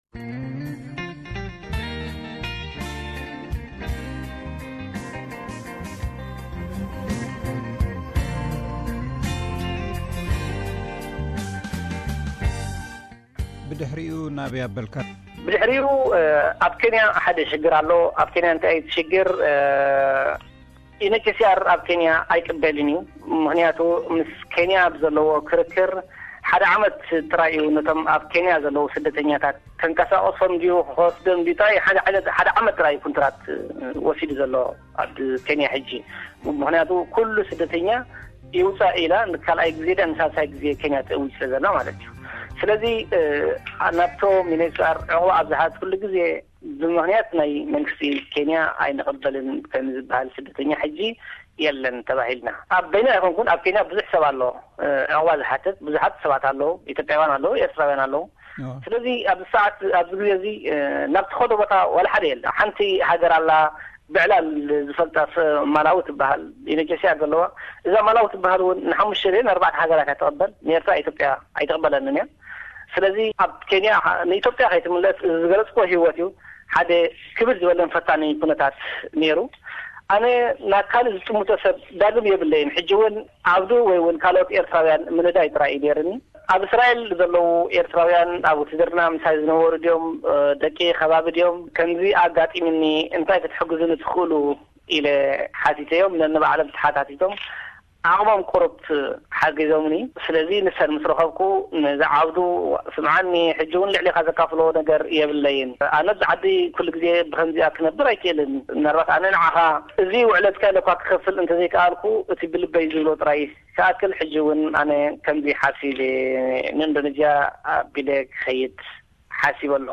Interview with Eritrean Asylum Seeker from Indonesia Part 3